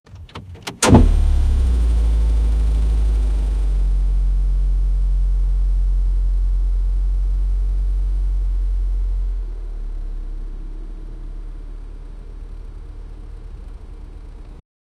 Electric-vehicles Sound Effects - Free AI Generator & Downloads
the-electric-car-starts-d-jdrcaar4.wav